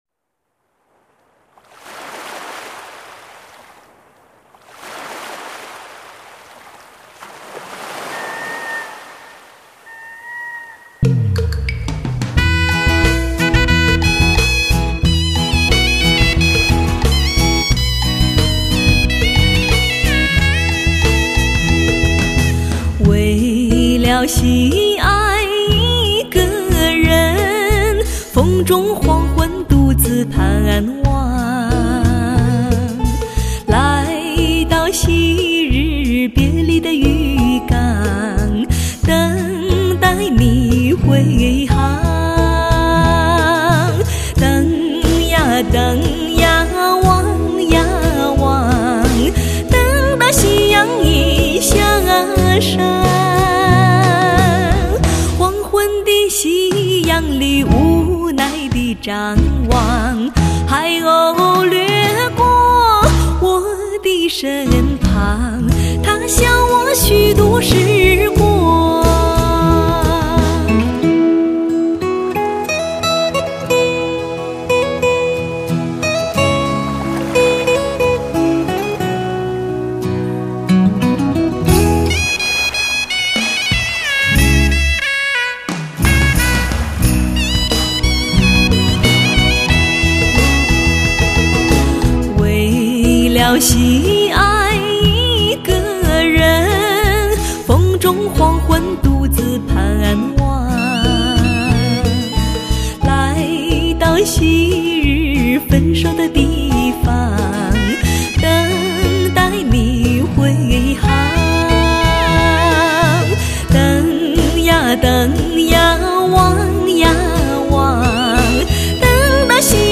极致发烧人声 让耳朵享受顶级音色
全新演绎优美的犹如抒情诗般的旋律
清透的音乐飘然入耳